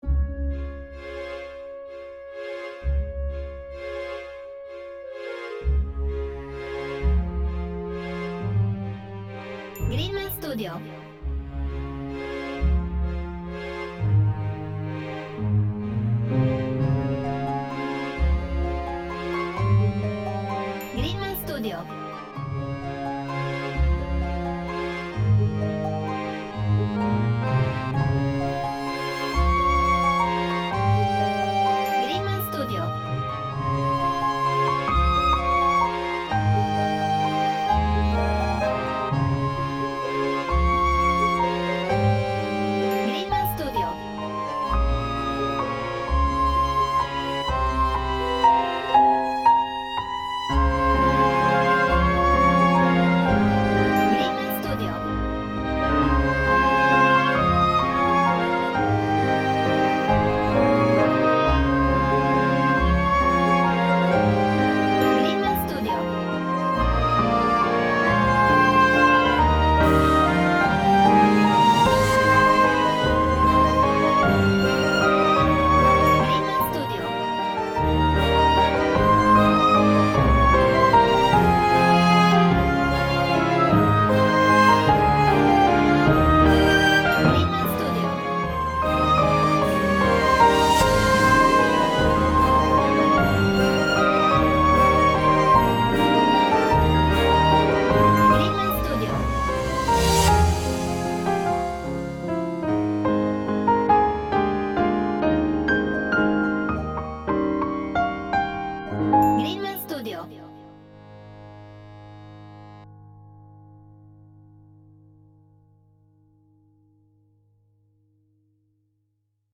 Orchestral/Cinematic